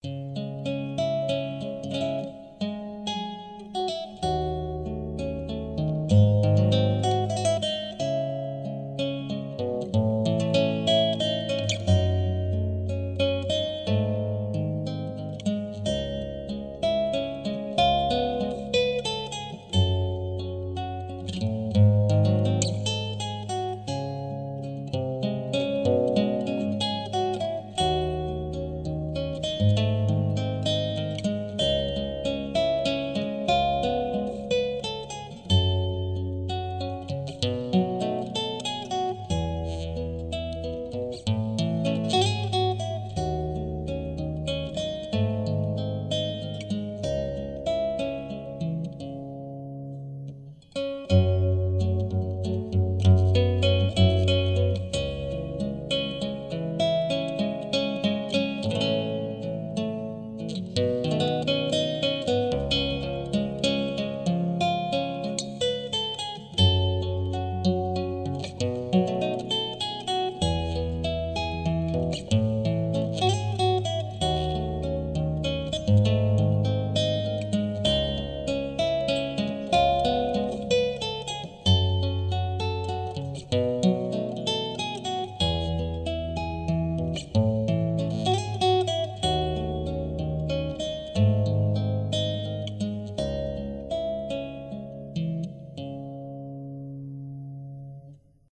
(инструментал)